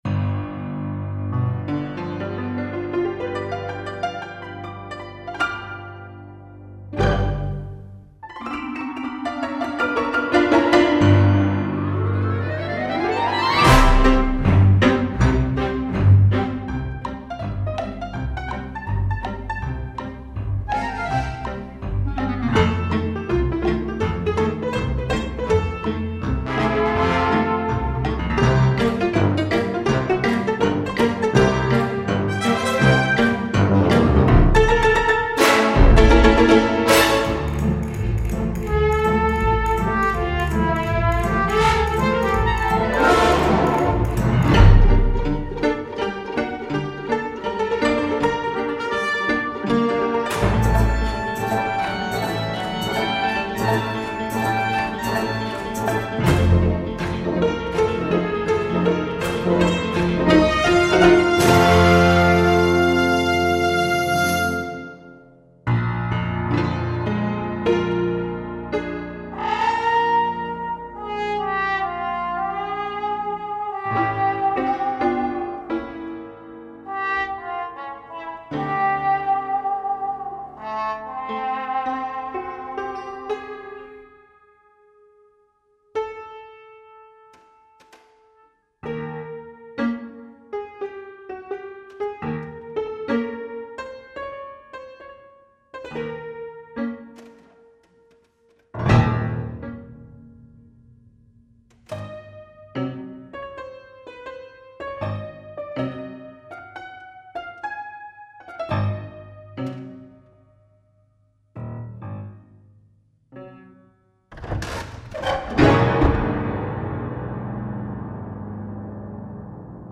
Soundiron The Drinking Piano 是一个钢琴音源库，它模拟了一种老式的啤酒厅的声音，让人想起19世纪末和20世纪初的酒馆、拉格泰姆、乡村音乐和无声电影的音乐。它采样了一架历史悠久的纯桃花心木制的 Ivors and Pond 超大型立式钢琴，它有着一百年来被疯狂演奏的痕迹。每个键都有一个独特的老化的特征，键盘的动作松散，阻尼器不可靠，锤子机制松动，毡布磨损，还有很多灰尘。它用大振膜麦克风在一个小的有一点反射的大厅里录制，捕捉了近距离的干净的录音室录音的氛围，同时在尾音中留有一点现场的氛围。